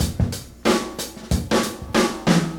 • 92 Bpm Drum Groove D Key.wav
Free breakbeat - kick tuned to the D note. Loudest frequency: 1924Hz
92-bpm-drum-groove-d-key-Bsj.wav